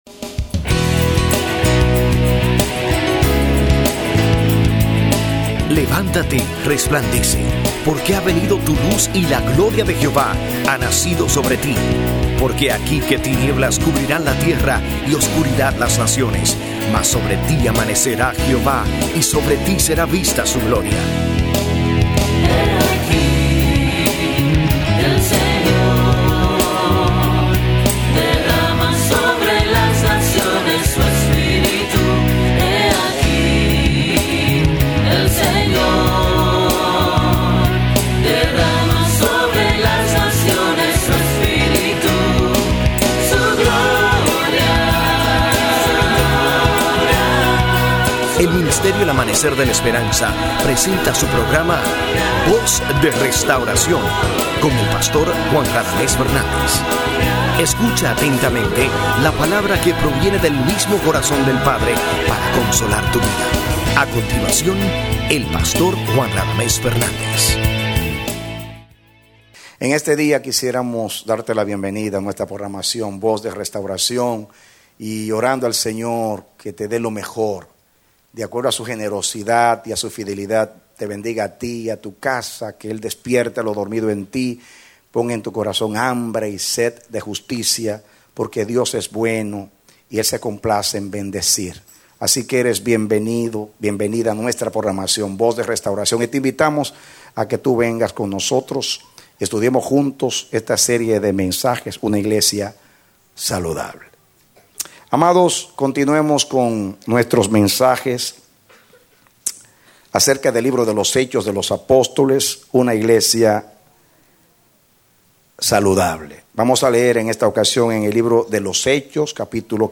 Serie de 50 Predicado Diciembre 07, 2010